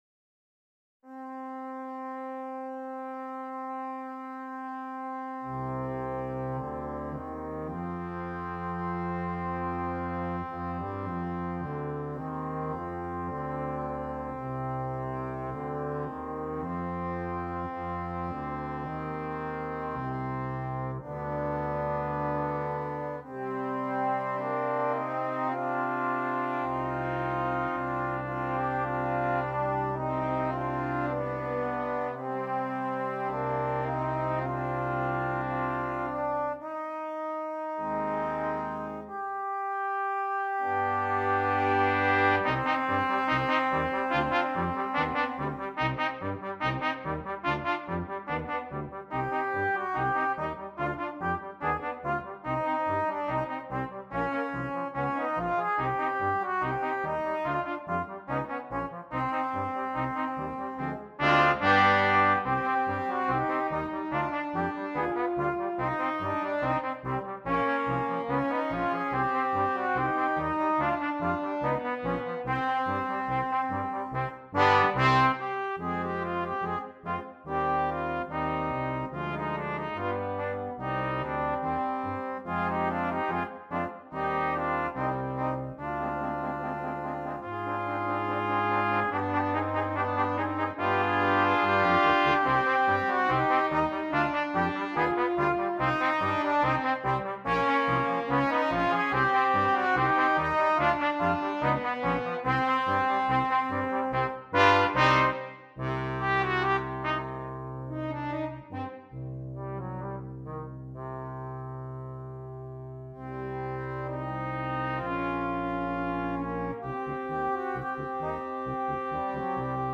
Gattung: Für Blechbläserquintett
Besetzung: Ensemblemusik für 5 Blechbläser